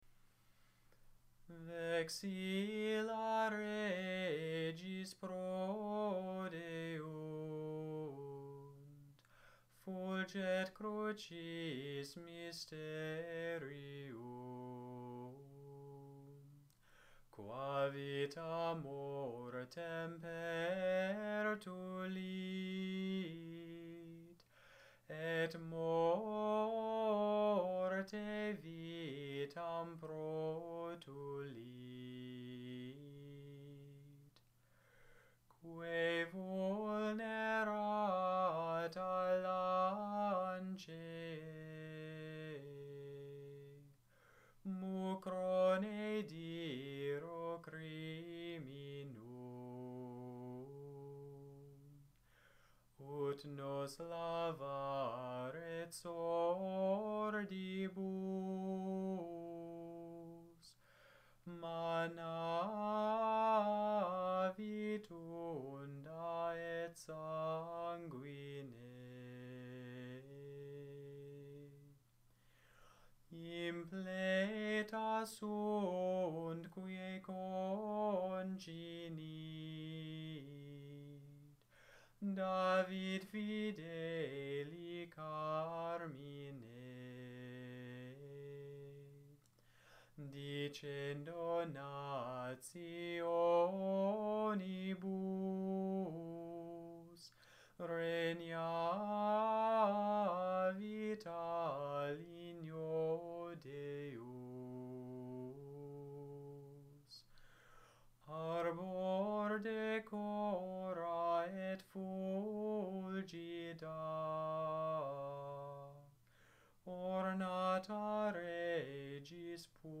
Gregorian, Catholic Chant Vexilla Regis